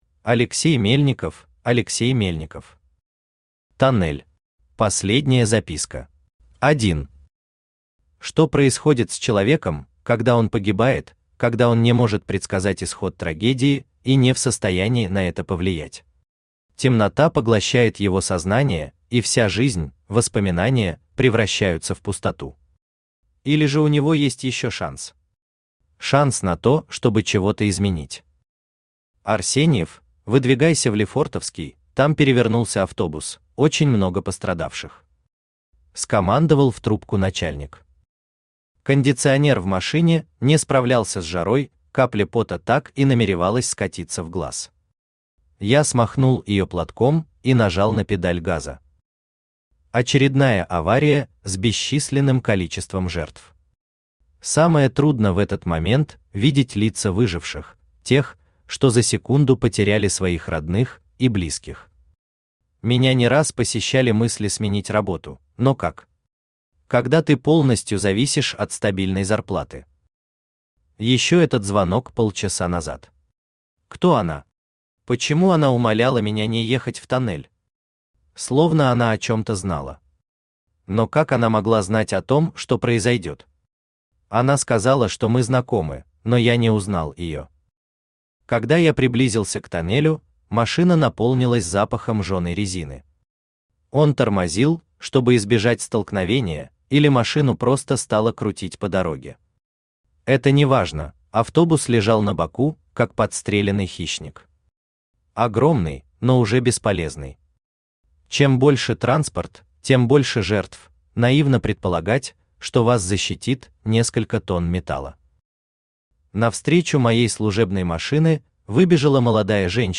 Аудиокнига Тоннель. Последняя записка | Библиотека аудиокниг
Последняя записка Автор Алексей Романович Мельников Читает аудиокнигу Авточтец ЛитРес.